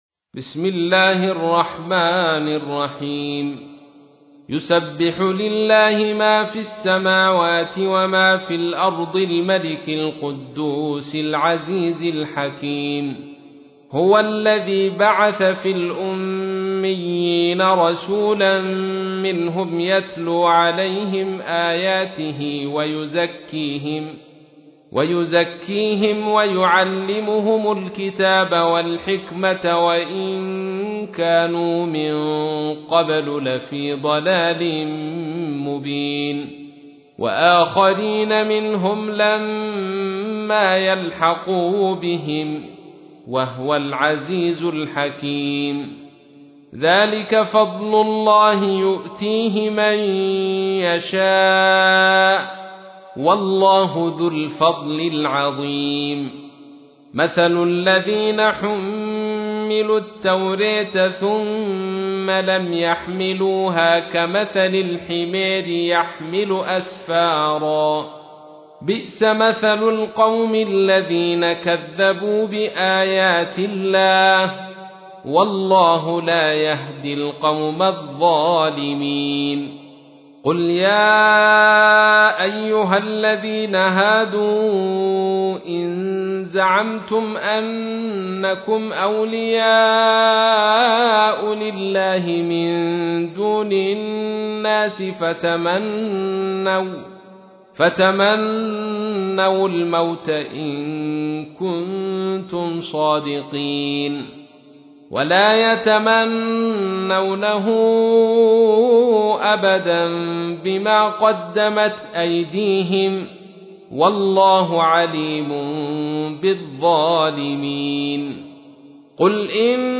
تحميل : 62. سورة الجمعة / القارئ عبد الرشيد صوفي / القرآن الكريم / موقع يا حسين